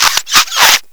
A creaking noise
creak.wav